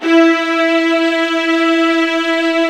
55u-va06-E3.aif